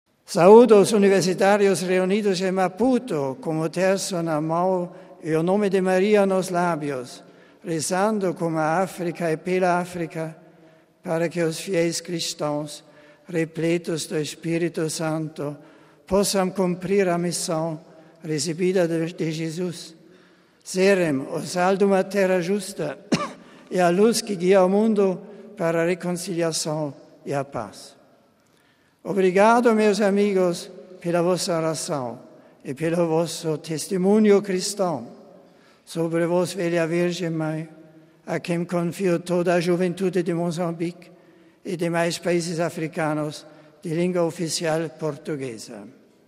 Cidade do Vaticano, 11 out (RV) - No final da tarde deste sábado, Bento XVI se reuniu com os universitários de Roma, na Sala Paulo VI, no Vaticano, para uma vigília mariana por ocasião da II Assembleia Especial para a África do Sínodo dos Bispos.
Saudando os jovens do continente africano, o papa falou também em português: "Saúdo os universitários reunidos em Maputo com o terço na mão e o nome de Maria nos lábios, rezando com a África e pela África, para que os fiéis cristãos, repletos do Espírito Santo, possam cumprir a missão recebida de Jesus: serem o sal duma terra justa e a luz que guia o mundo para a reconciliação e a paz.